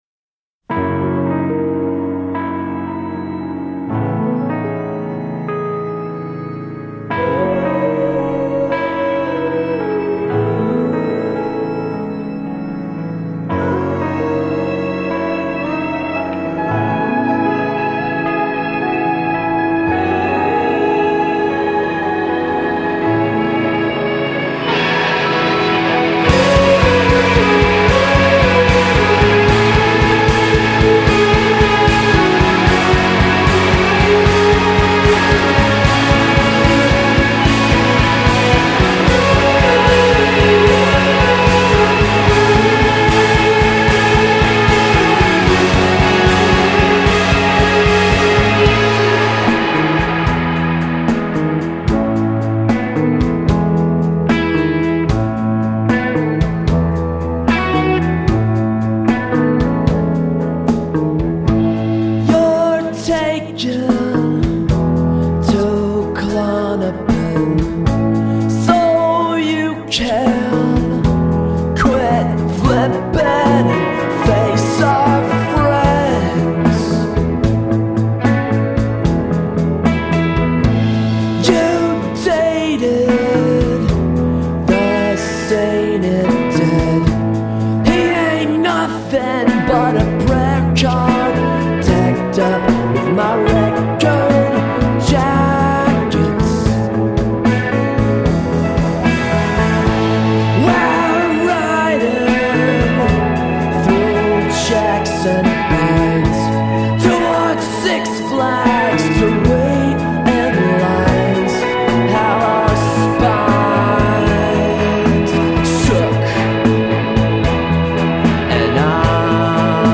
a softer falsetto